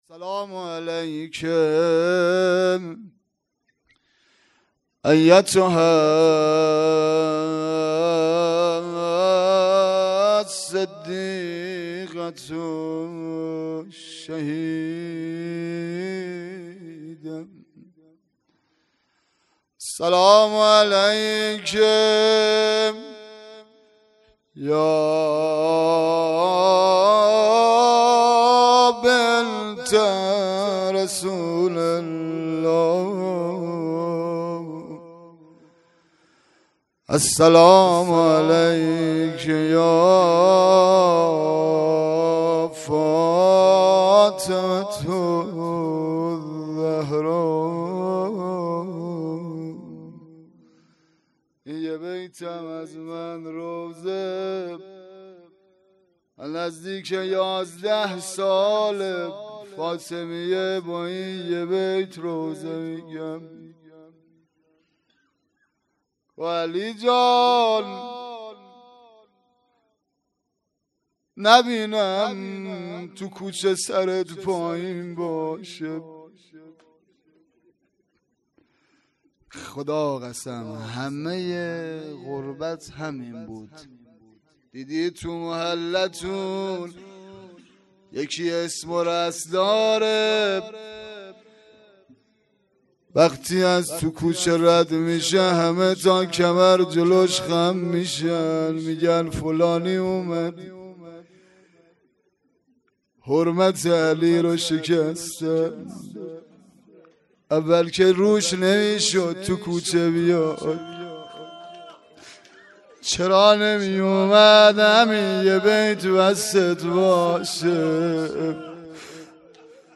مراسم شهادت حضرت زهرا سلام الله علیها فاطمیه دوم ۱۴۰۳